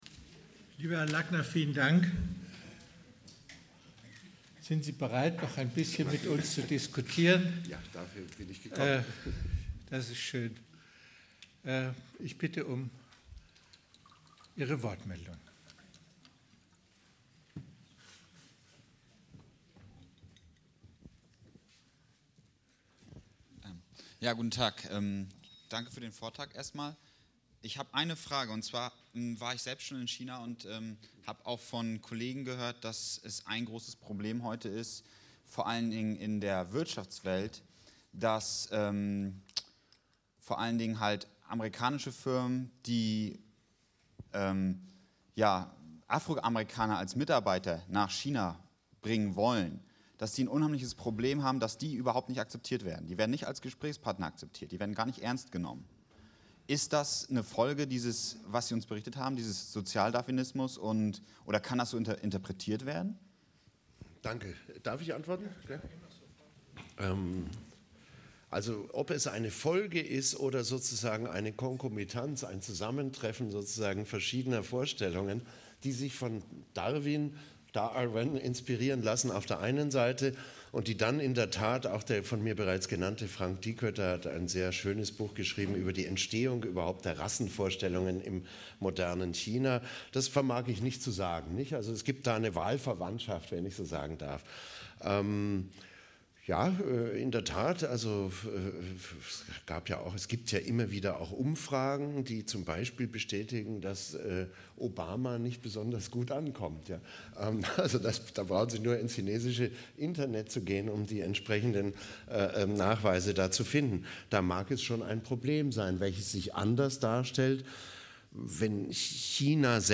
Thursday, Feb. 03, 2011, 7:00 p.m., Akademie der Wissenschaften in Hamburg, Esplanaden 15, Baseler Hof Säle, 20354 Hamburg Akademievorlesungen 2010/2011: Shanghai Express.
Introduction Lecture Q&A session Concluding remark